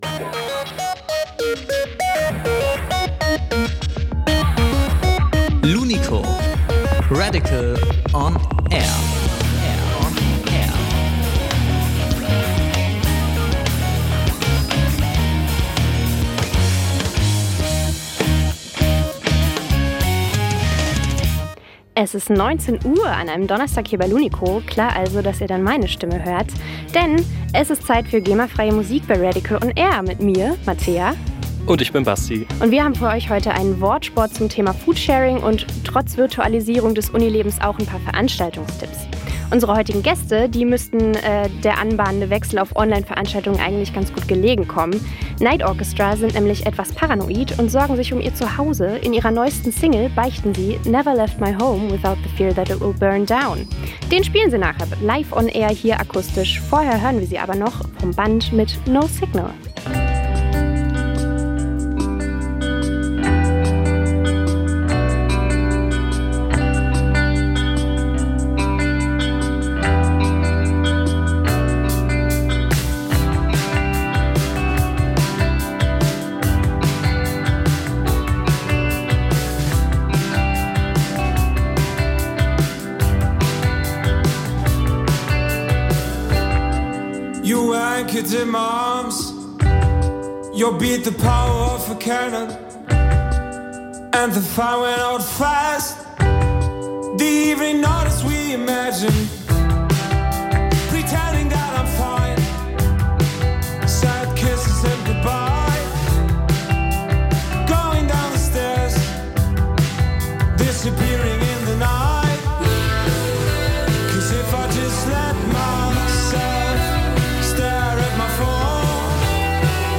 live im Studio NIGHT ORCHESTRA